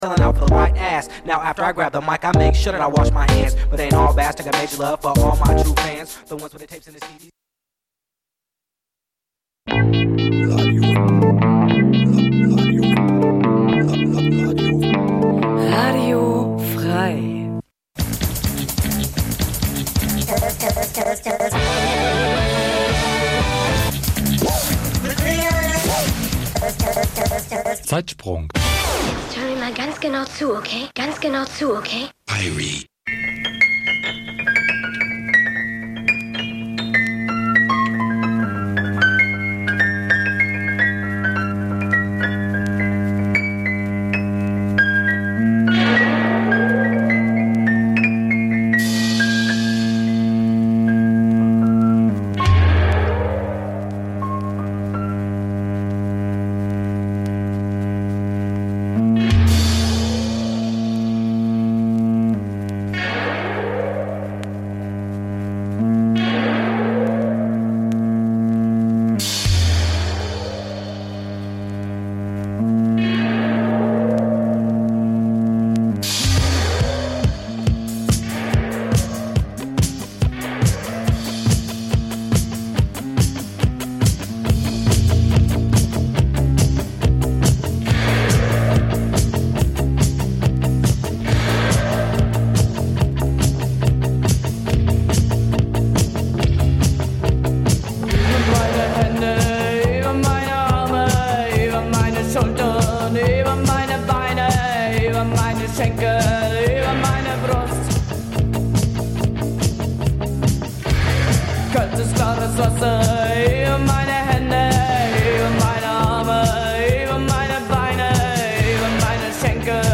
Schr�ge Originale stehen noch schr�geren Coverversionen gegen�ber.
Musik vergangener Tage Dein Browser kann kein HTML5-Audio.